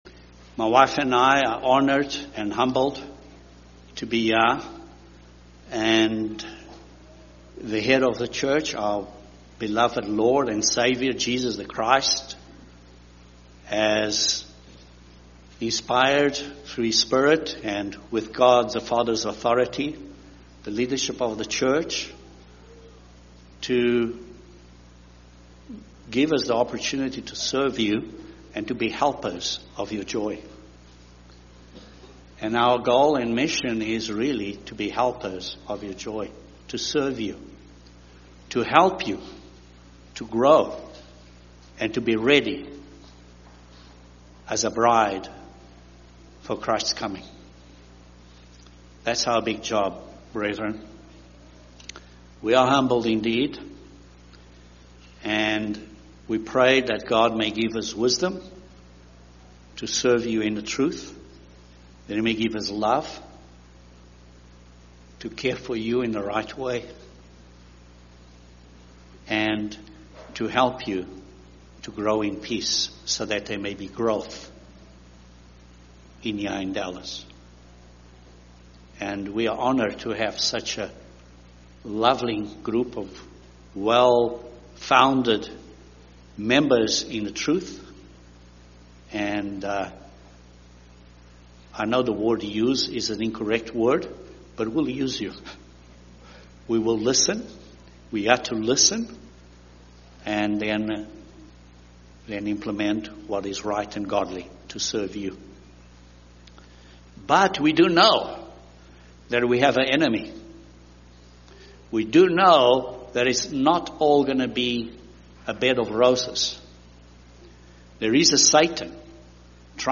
The sermon will address a few steps in the process to develop godly, holy, righteous character.